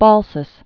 (bôlsəs, bäl-)